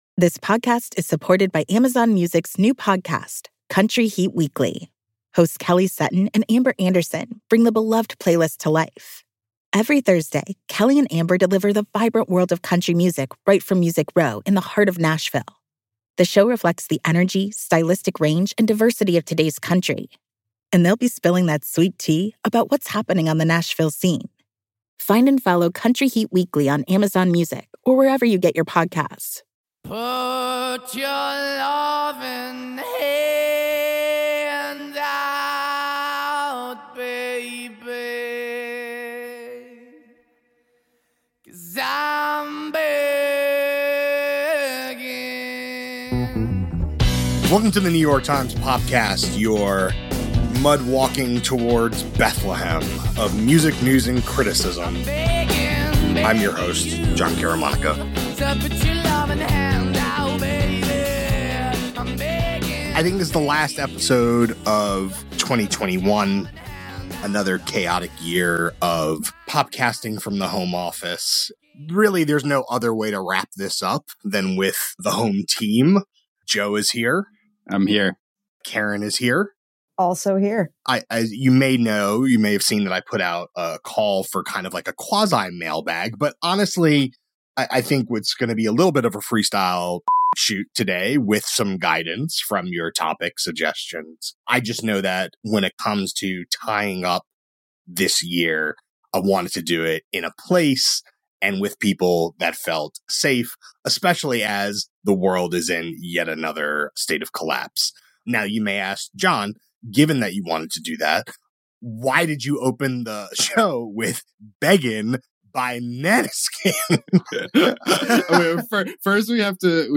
A catch-up round table on a host of topics: Maneskin, Jazmine Sullivan, Kelly Clarkson, a Real Housewife’s music career and much more.